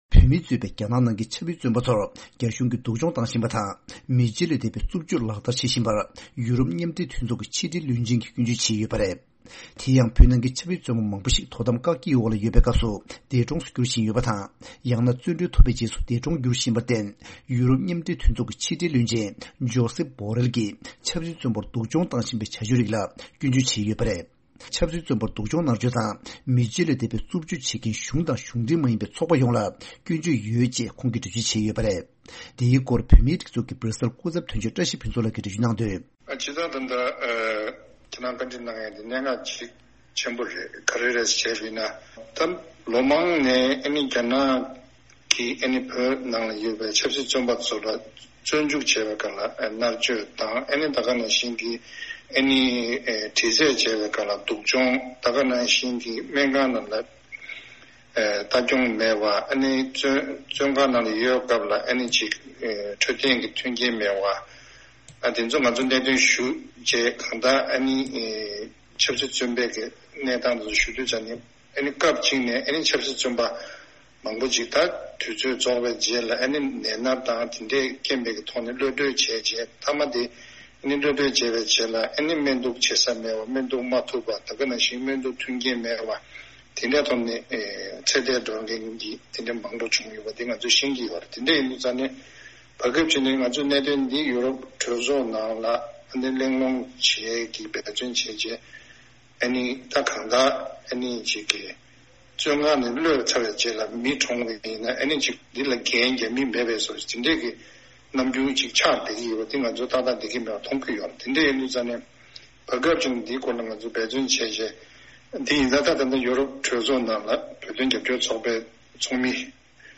ཡུ་རོབ་སྐུ་ཚབ་དོན་གཅོད་ལ་བཅར་འདྲི་ཞུས་ཏེ་གནས་ཚུལ་ཕྱོགས་བསྒྲིགས་བྱས་པ་ཞིག་གསན་རོགས་གནང་།